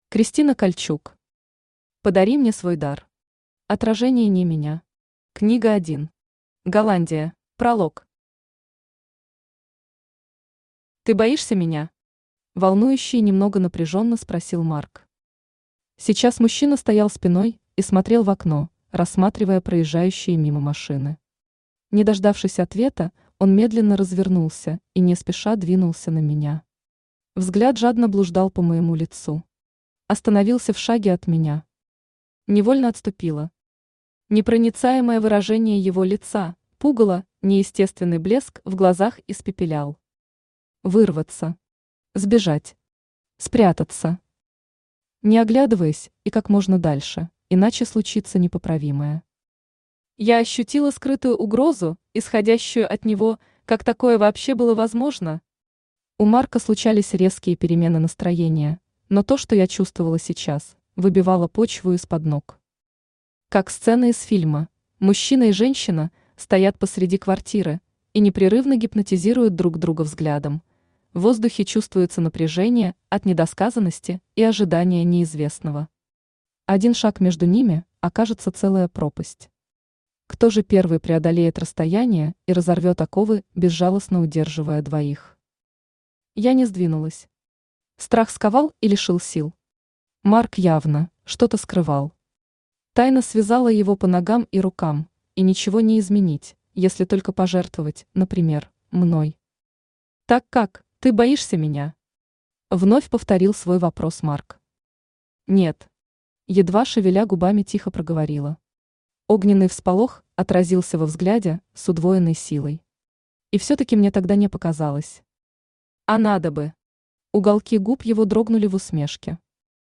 Аудиокнига Подари мне свой дар. Отражение не меня. Книга 1. Голландия | Библиотека аудиокниг
Голландия Автор Кристина Кальчук Читает аудиокнигу Авточтец ЛитРес.